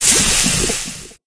rogue_anomaly_acid.ogg